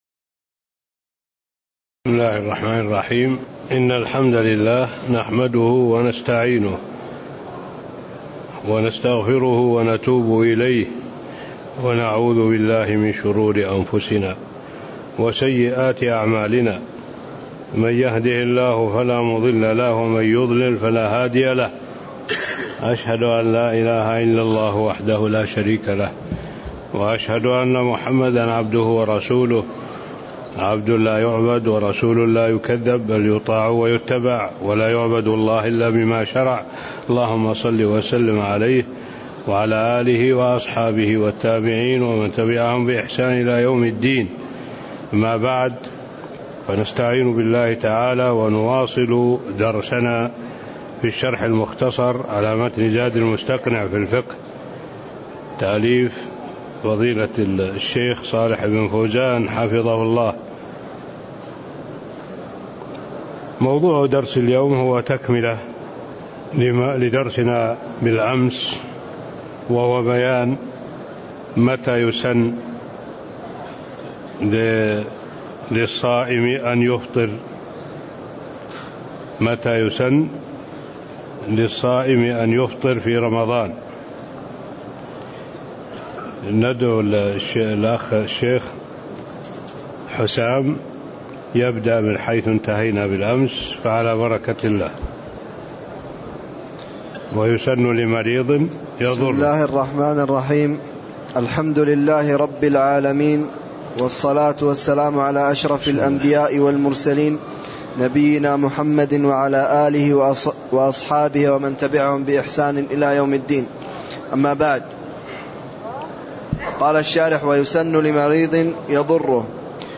تاريخ النشر ٢٨ شعبان ١٤٣٤ هـ المكان: المسجد النبوي الشيخ: معالي الشيخ الدكتور صالح بن عبد الله العبود معالي الشيخ الدكتور صالح بن عبد الله العبود بيان متى يسن لصائم أن يفطر (07)